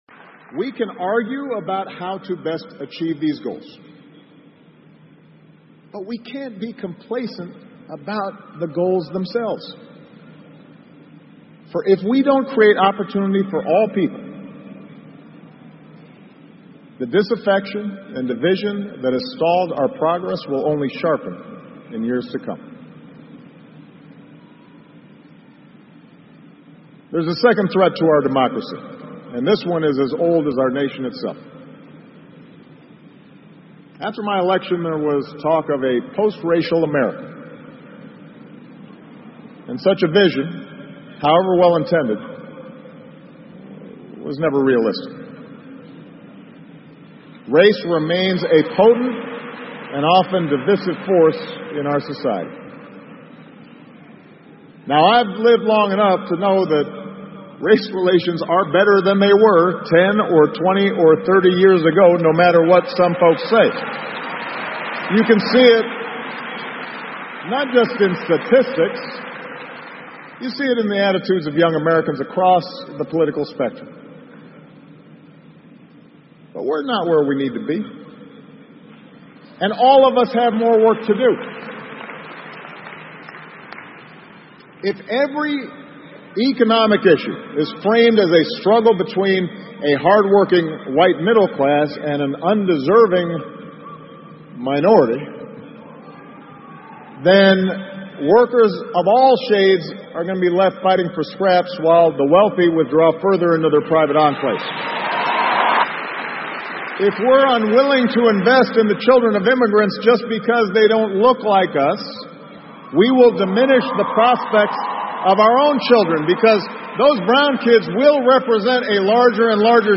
奥巴马每周电视讲话：美国总统奥巴马告别演讲(8) 听力文件下载—在线英语听力室